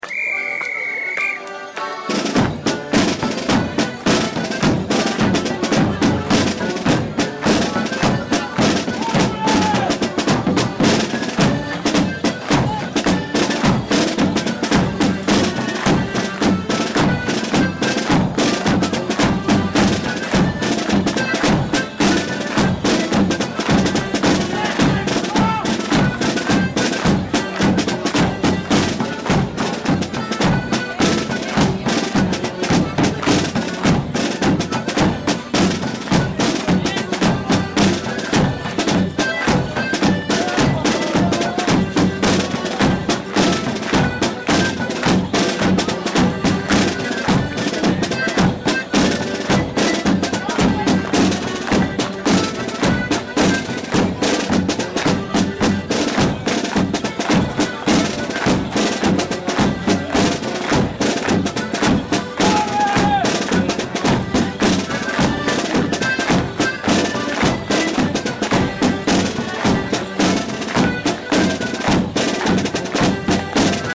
Whistles
aud_whistle_music.mp3